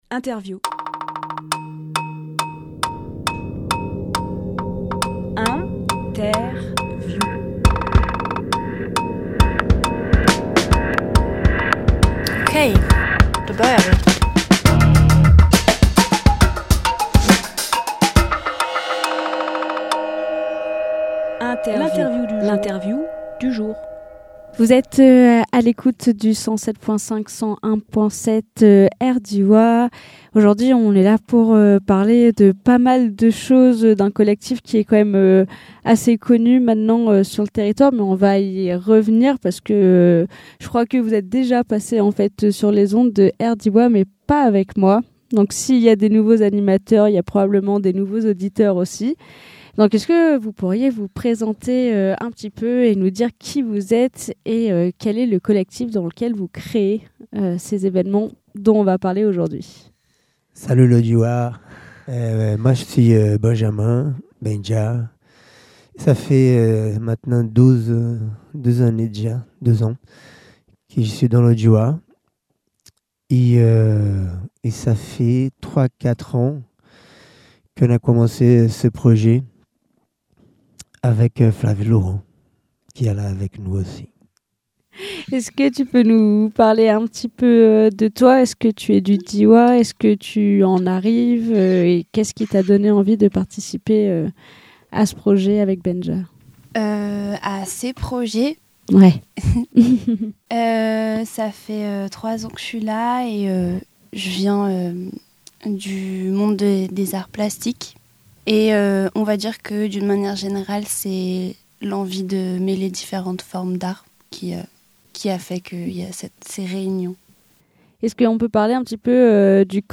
Emission - Interview Baluê | Diferrance – Un été culturel Publié le 19 juillet 2023 Partager sur… Télécharger en MP3 Un été culturel, avec et pour une mixité de collectifs artistique !